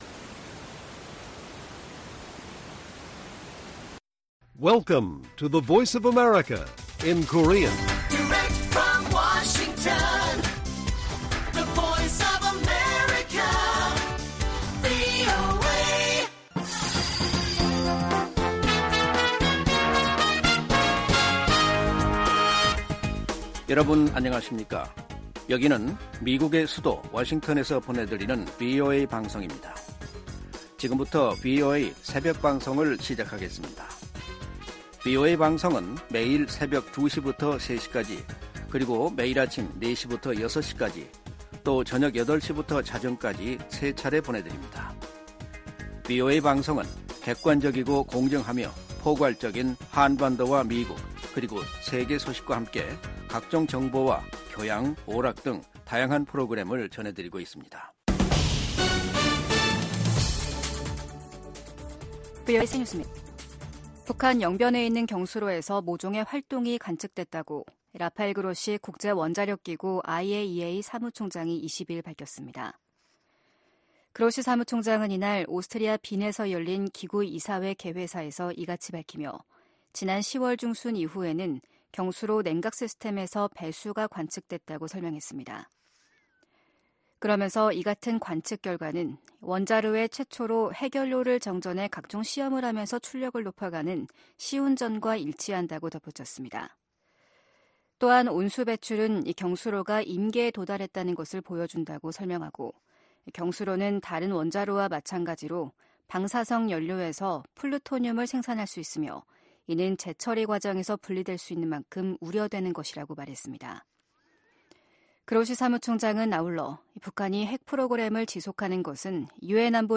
VOA 한국어 '출발 뉴스 쇼', 2023년 12월 23일 방송입니다. 미국이 탄도미사일 개발과 발사가 방위권 행사라는 북한의 주장을 '선전이자 핑계일 뿐'이라고 일축했습니다. 미 국방부는 북한 수뇌부를 제거하는 '참수작전'이 거론되자 북한에 대해 적대적 의도가 없다는 기존 입장을 되풀이했습니다. 이스라엘 정부가 북한의 탄도미사일 발사를 '테러 행위'로 규정했습니다.